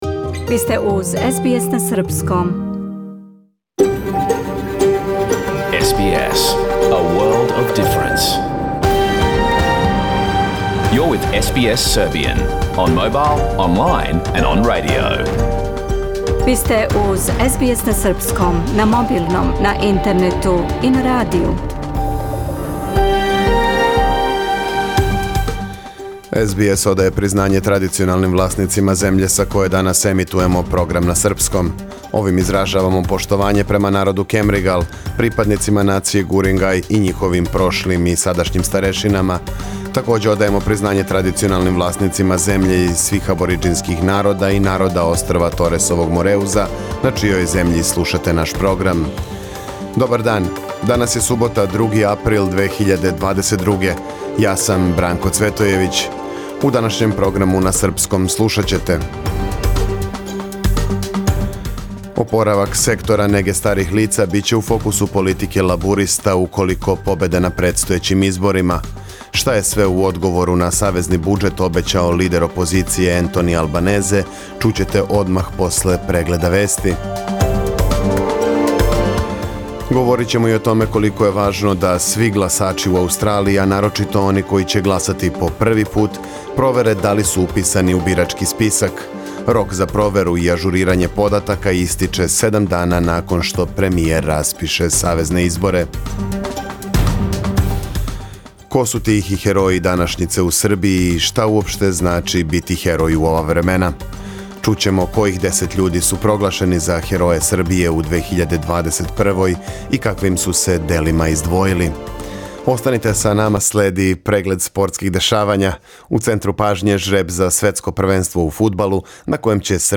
Програм емитован уживо 2. априла 2022. године
Ако сте пропустили нашу емисију, сада можете да је слушате у целини као подкаст, без реклама.